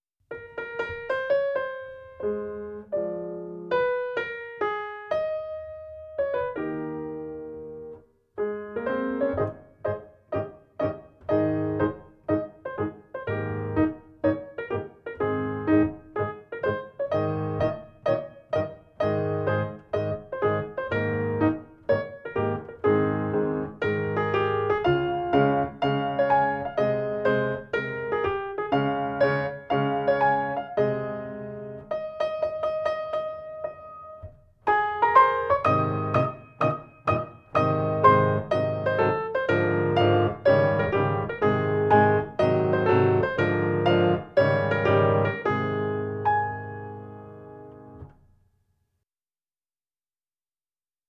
20_Lallarallarà_base
20_Lallarallarà_base.mp3